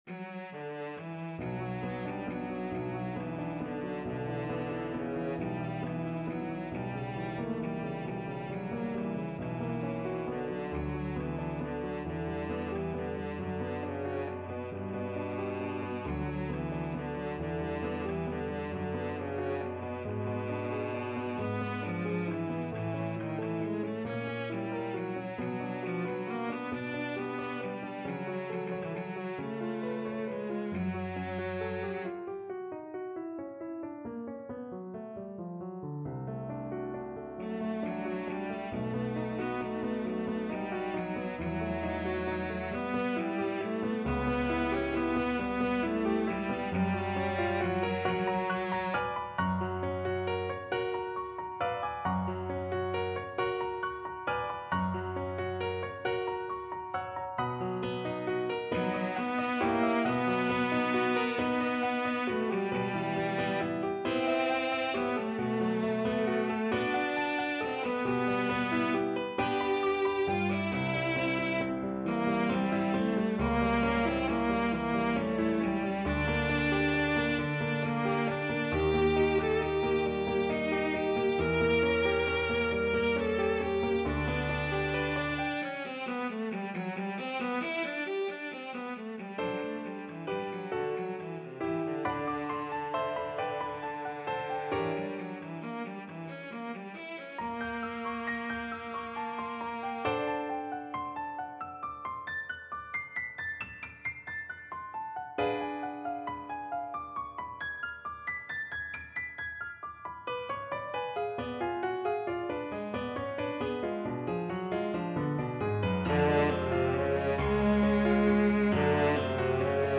6/8 (View more 6/8 Music)
Andantino .=45 (View more music marked Andantino)
B3-B5
Classical (View more Classical Cello Music)